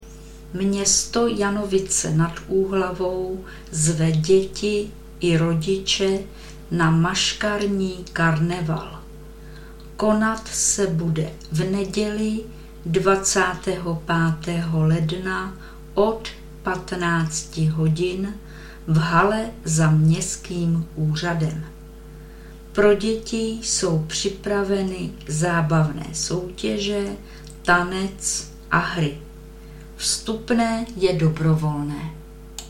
Hlášení místního rozhlasu - Dětský maškarní karneval dne 25.ledna v Janovicích nad Úhl.